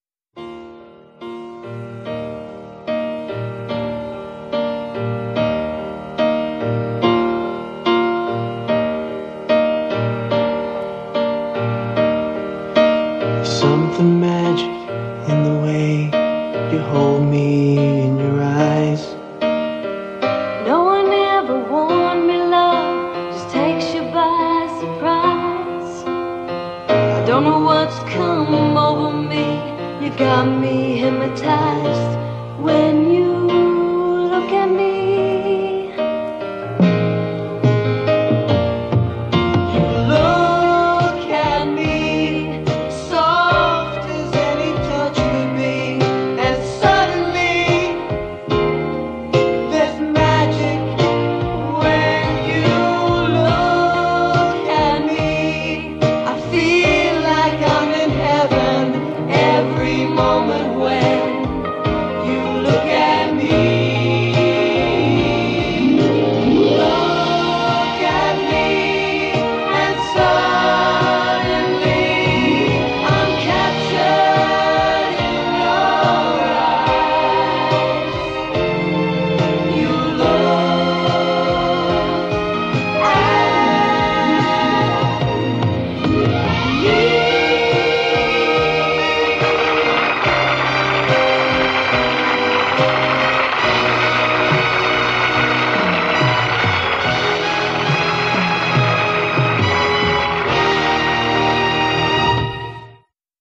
Tags: TV Songs actors theme song show